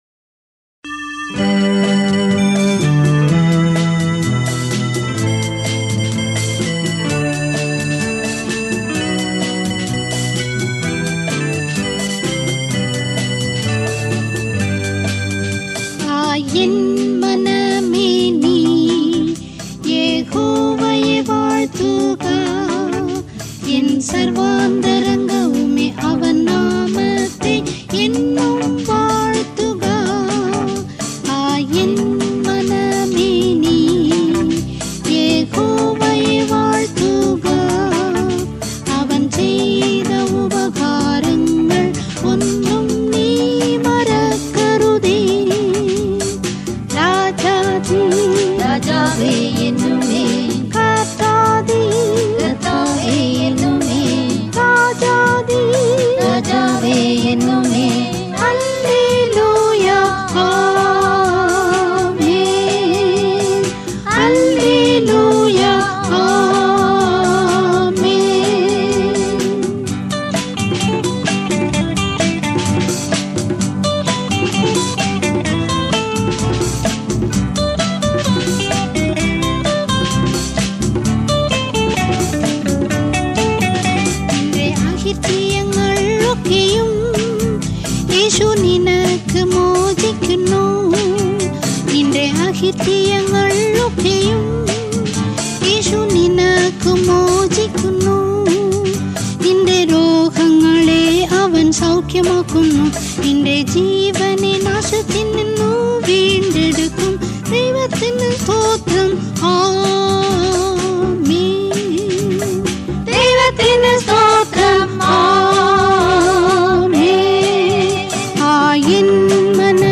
Royalty-free Christian music available for free download.